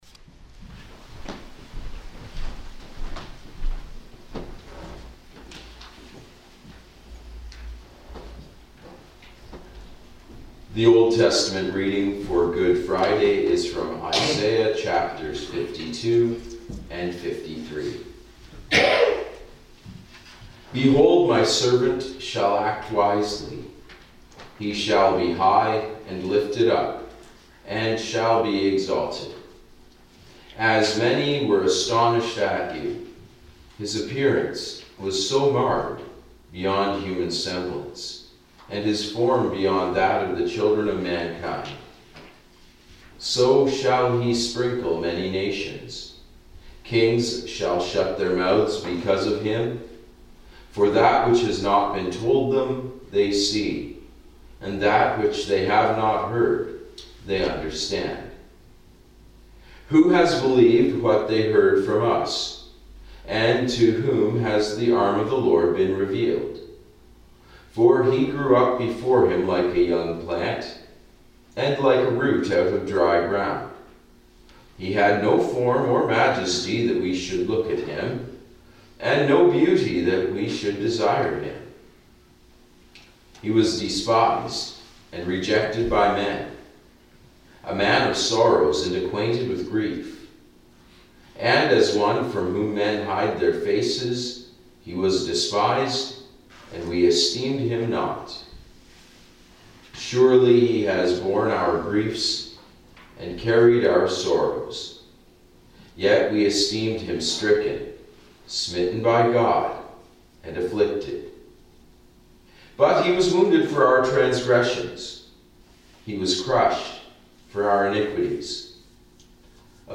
Good Friday April 3, 2026 Readings: Isaiah 52:13-53:12, Hebrews 4:14-16 & 5:7-9, John 18-19 Sermon: The Suffering Servant (Isaiah 53:4-10)...
Readings and Sermon – April 3, 2026